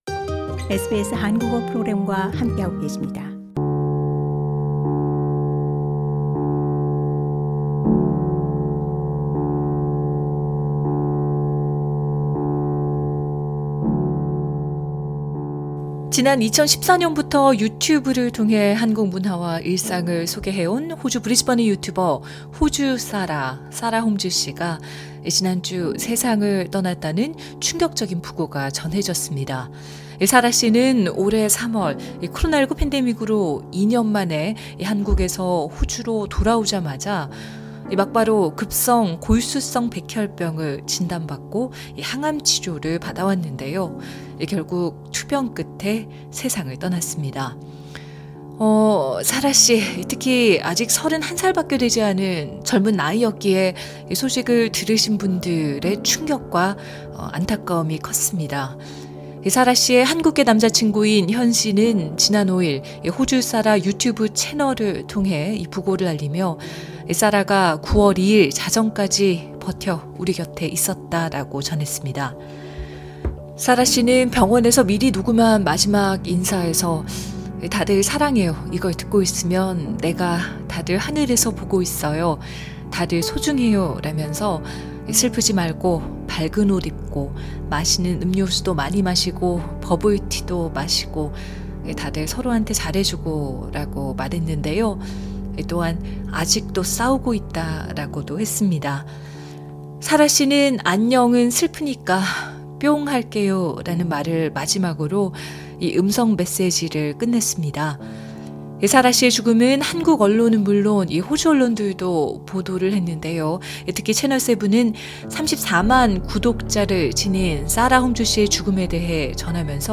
인터뷰 하일라이트